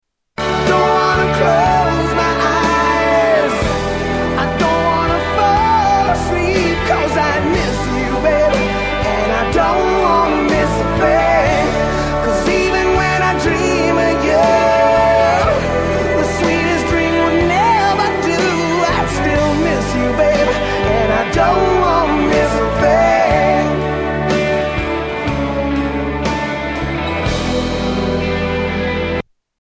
Rock Songs